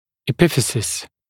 [ɪˈpɪfɪsɪs] [e-][иˈпифисис] [э-]эпифиз (суставной конец кости)